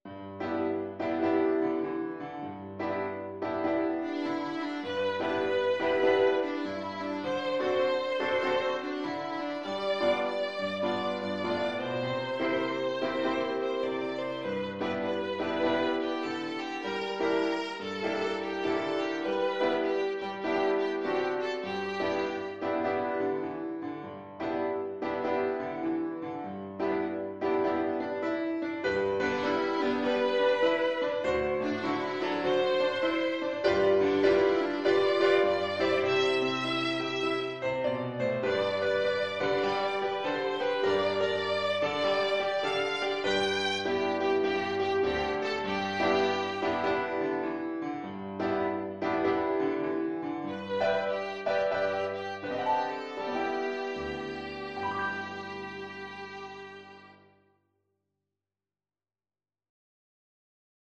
Traditional Trad. Tohora nui Violin version
Violin
G major (Sounding Pitch) (View more G major Music for Violin )
Moderate swing
4/4 (View more 4/4 Music)
Traditional (View more Traditional Violin Music)
world (View more world Violin Music)
tohora_nui_VLN.mp3